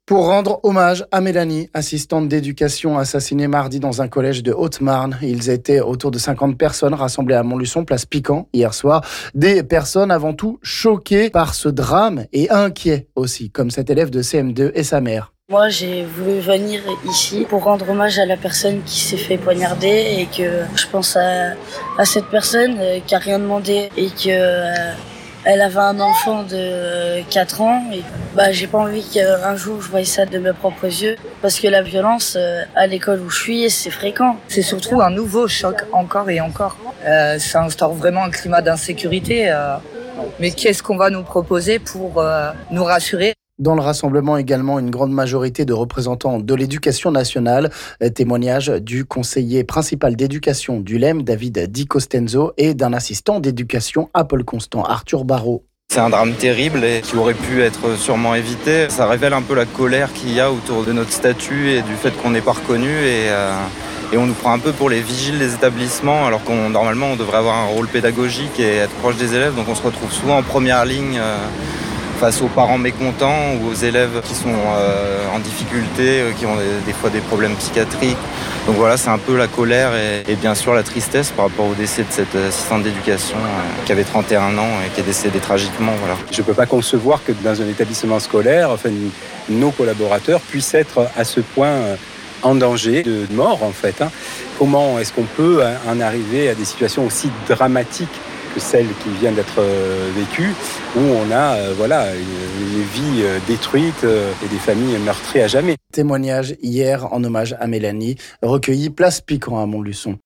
Elèves, parents, et de nombreux personnels de l’Education Nationale évidemment qui dénoncent par la même occasion la situation précaire et trop exposée des AED face à des élèves de plus en plus violents parfois.